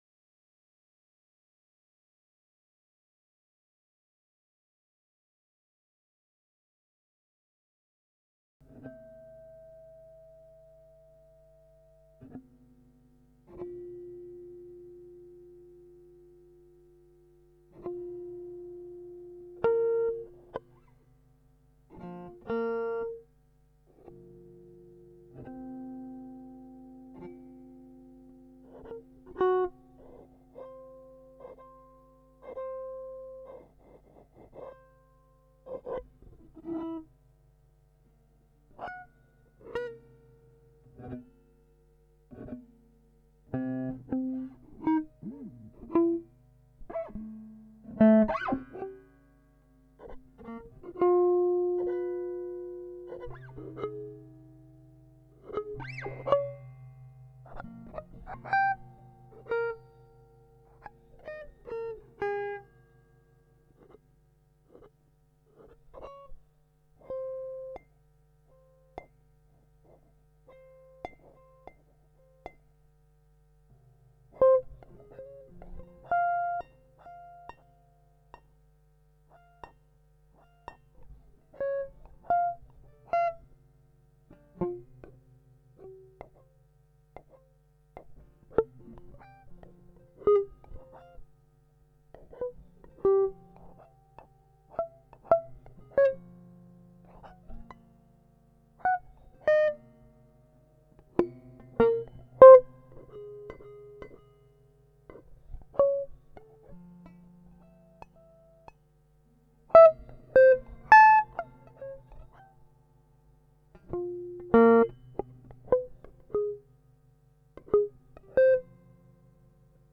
sound knife in the sand